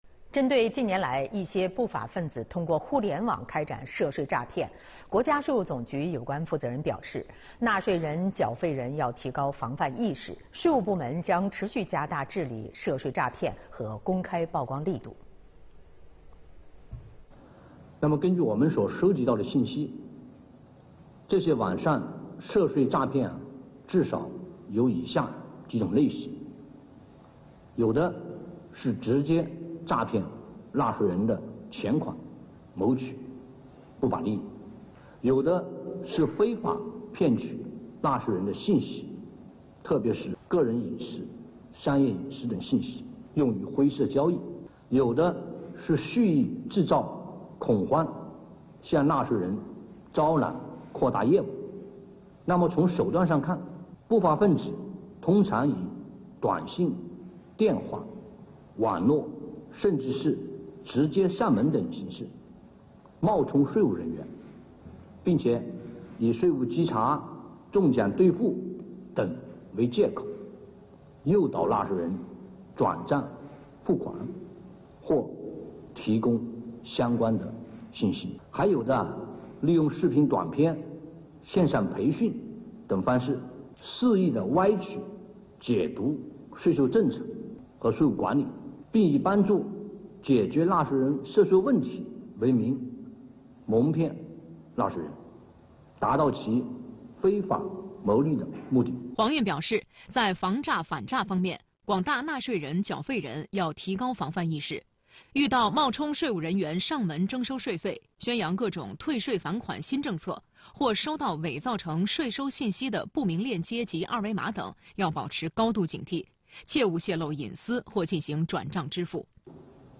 2月21日，国家税务总局召开新闻发布会，介绍了2023年“便民办税春风行动”接续推出的第二批25条便民服务措施以及“一带一路”税收征管合作机制推出的新举措等相关内容。